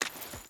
Water Chain Run 3.wav